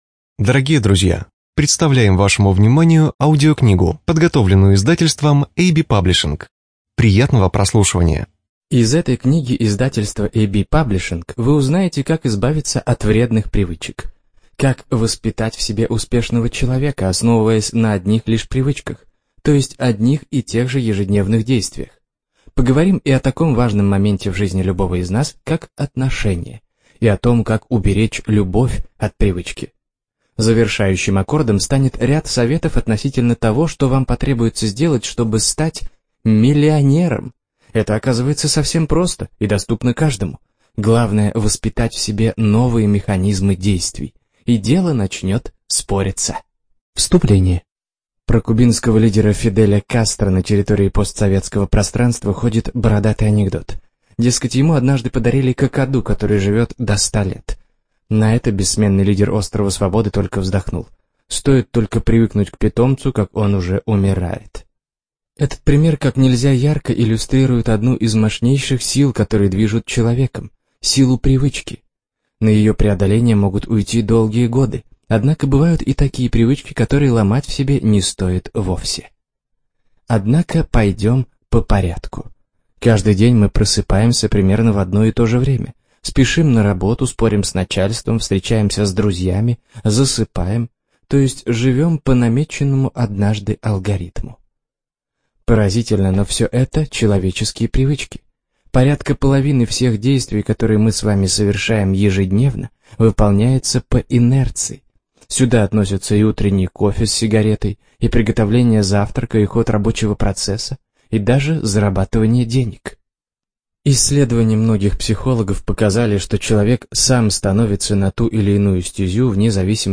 Студия звукозаписиAB-Паблишинг